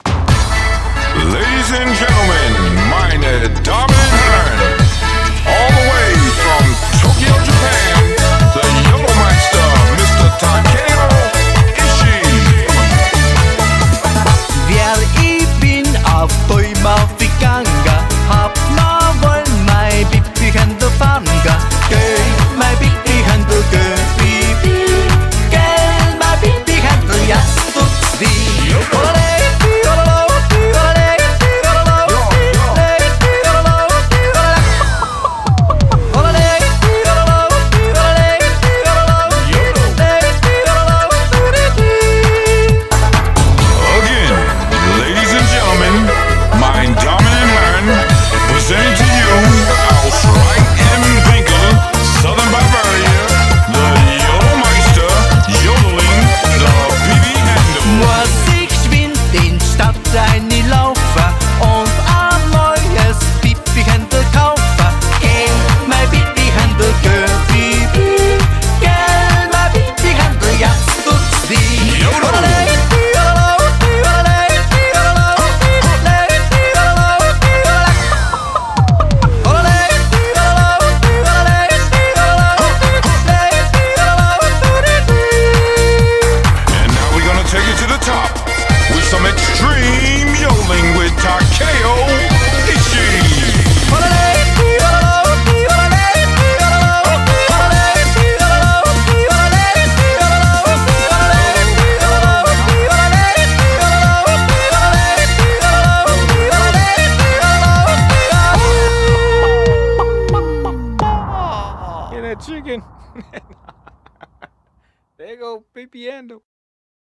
BPM133
Comments[CHICKEN YODELING]